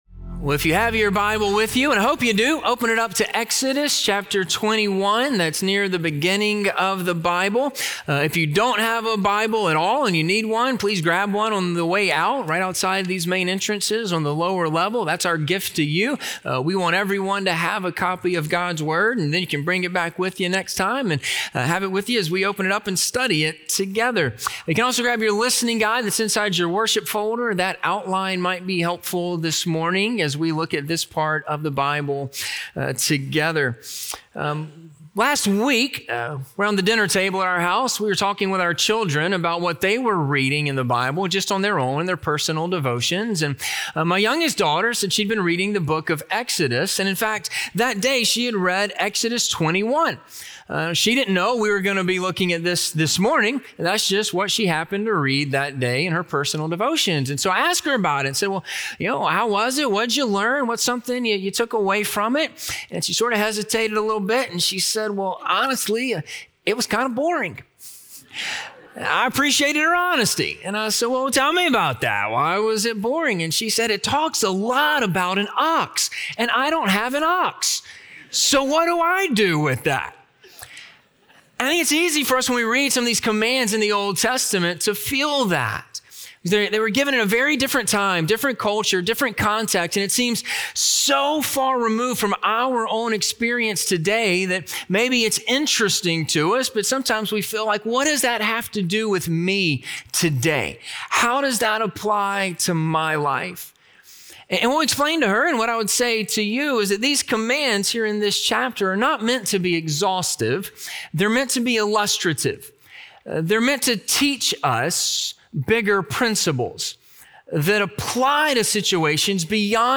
A Just and Loving Society - Sermon - Ingleside Baptist Church